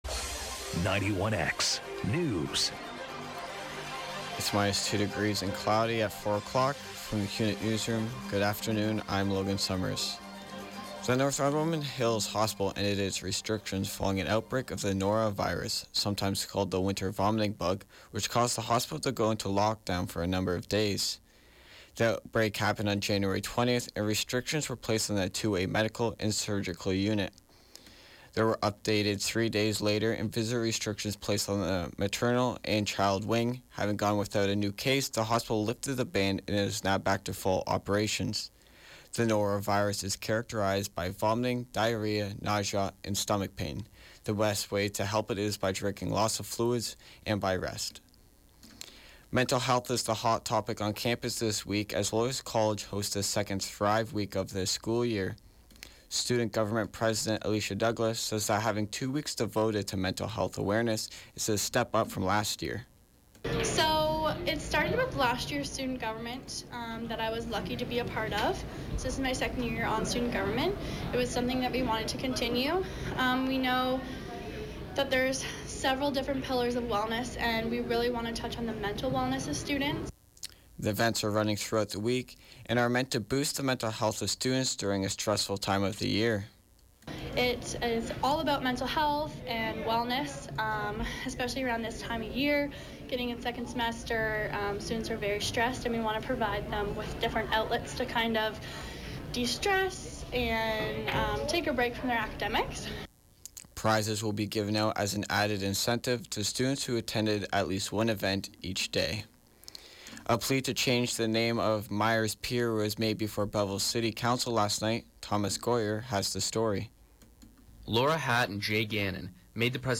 91X Newscast: Tuesday, Jan. 28, 2020, 4 p.m.